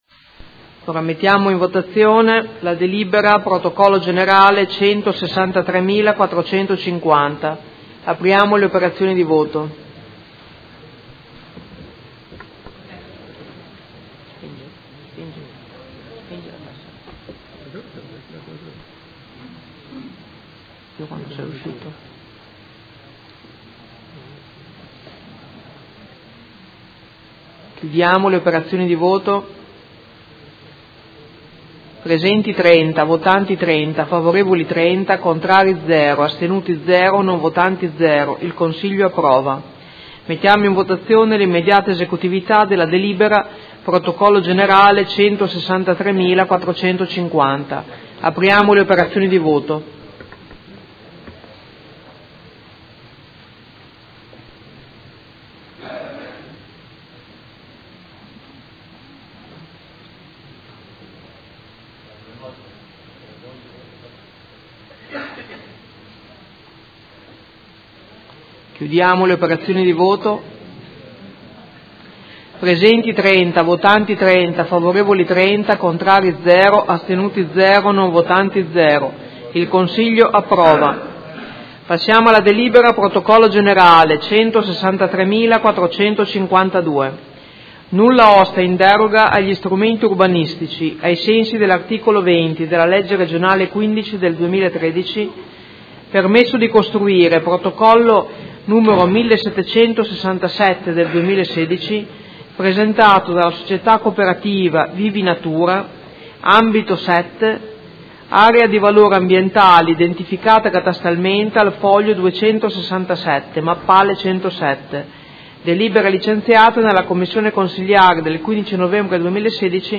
Presidente